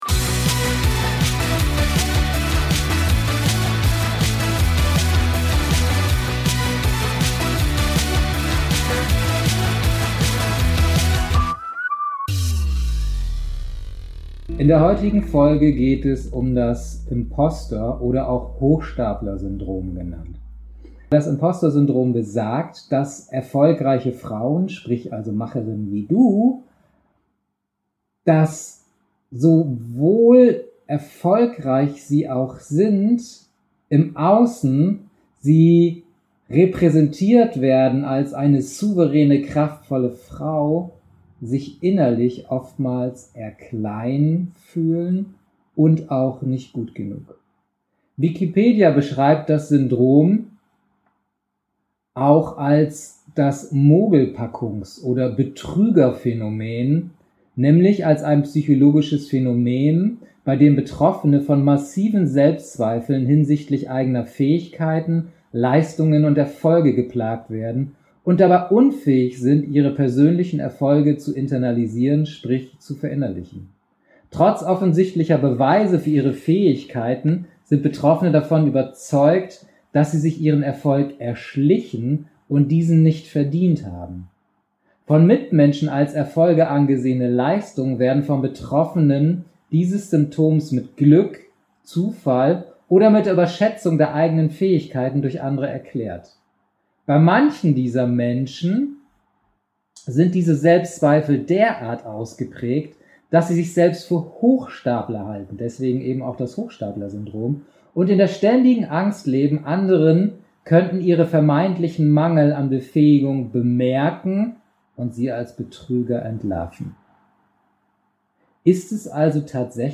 Sorry für die nicht so gelungene Aufnahmequalität dieses Mal.